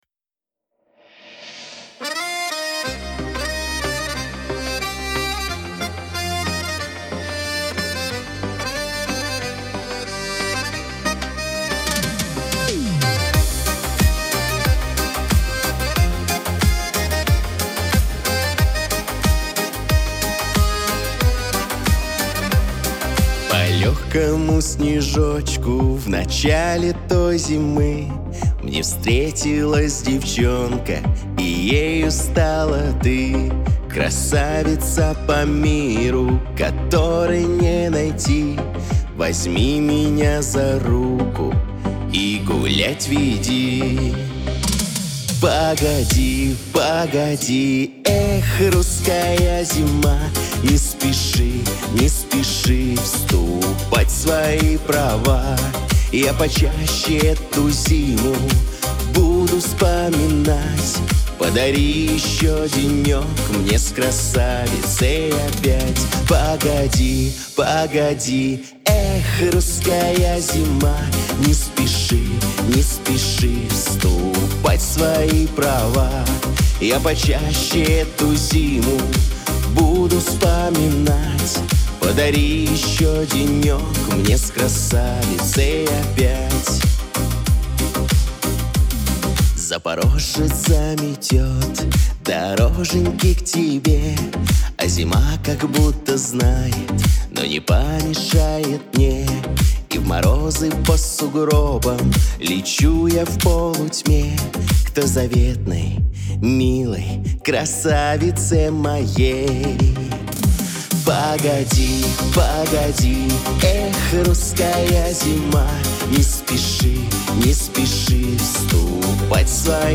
Лирика , диско , pop